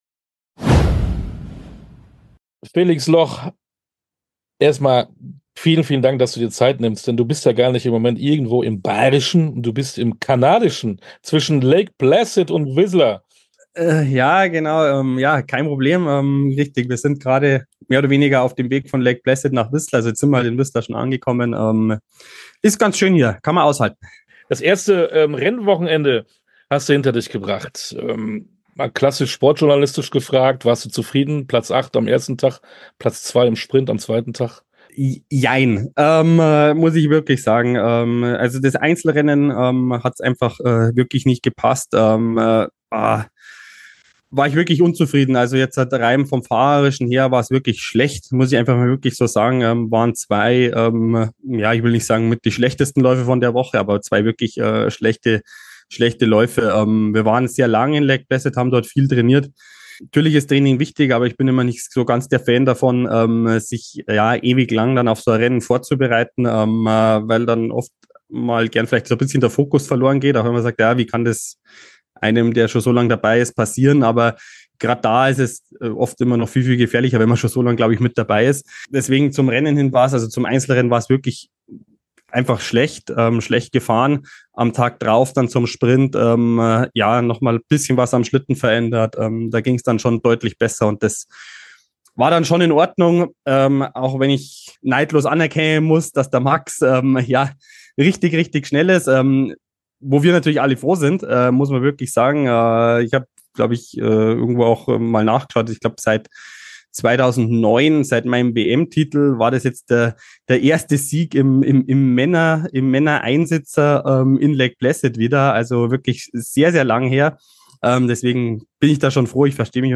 Sportstunde - Interviews in voller Länge
hier das Interview mit Felix Loch